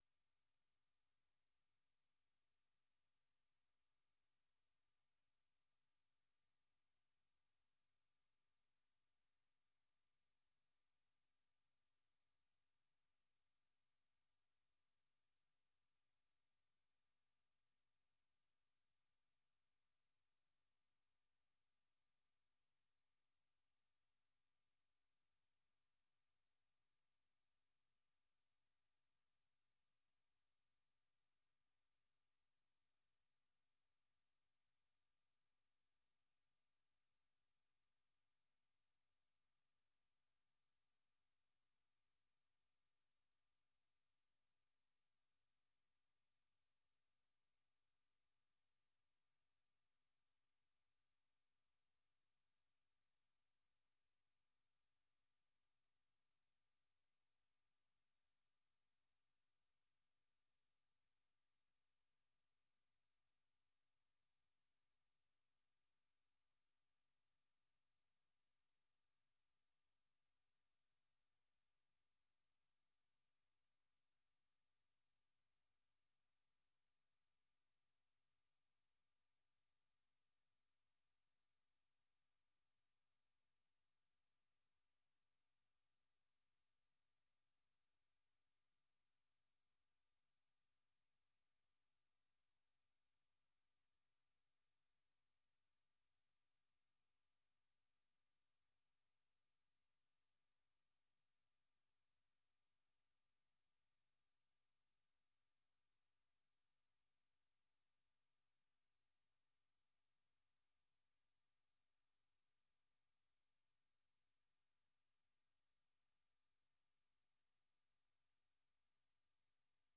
Learning English programs use a limited vocabulary and short sentences. They are read at a slower pace than VOA's other English broadcasts.